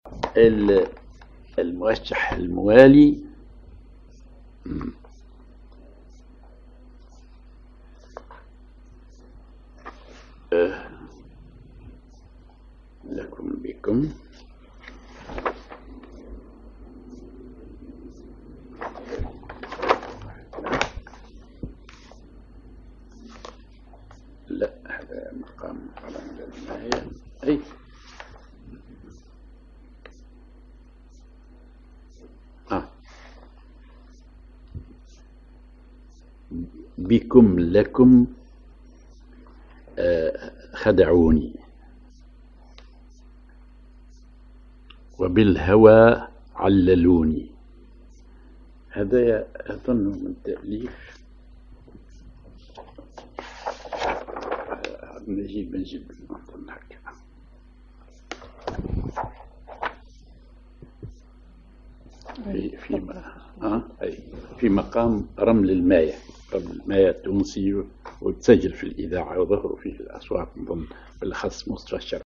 Maqam ar رمل الماية مغاربي
Rhythm ar بطايحي
genre موشح